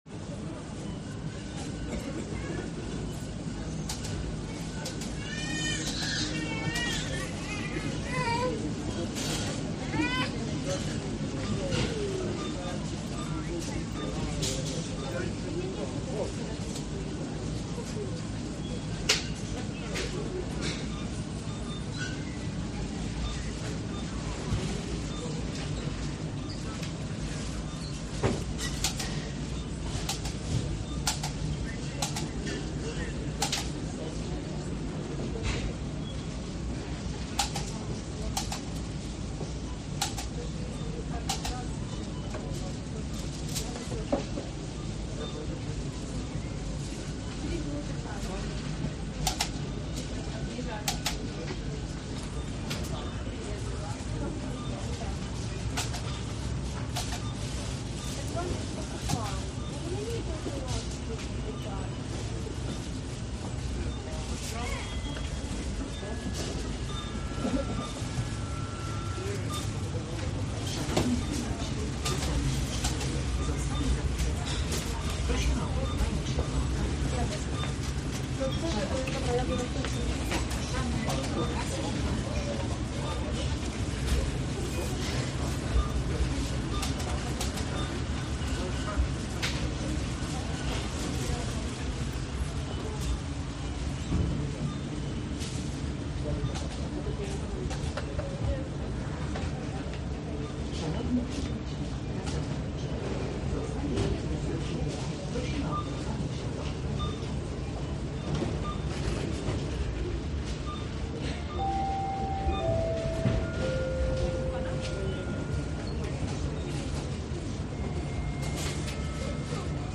W supermarkecie Lidl mimo wyłączenia muzyki w dalszym ciągu odtwarzane były komunikaty głosowe informujące o zamknięciu lub otwarciu kas.
Każda informacja głosowa poprzedzona była brzmieniem rozłożonego trójdźwięku, który po wielokrotnym powtórzeniu stawał się uciążliwy.
Nagranie nr 1, sklep Lidl, ul. Jana Długosza, Wrocław, 9 listopada 2024, godz. 19:21, czas trwania: 2 min 16 s.